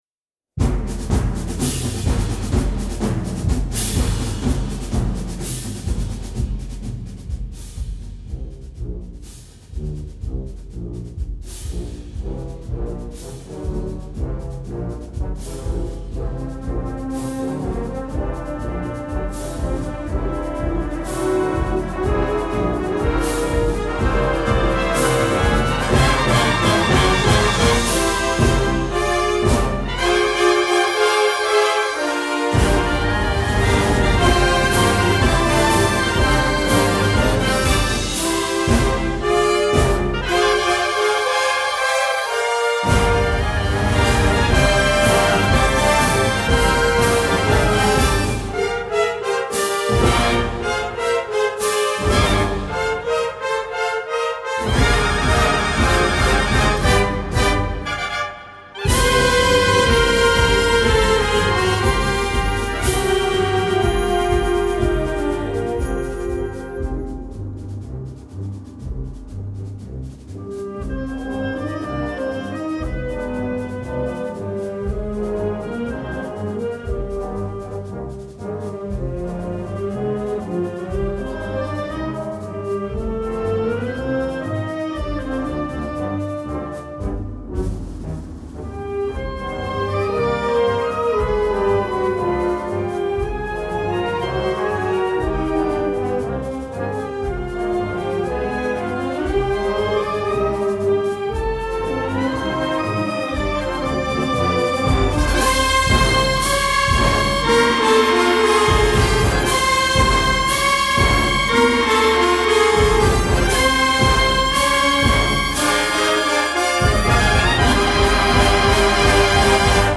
Moorish march
Gender: Moorish marches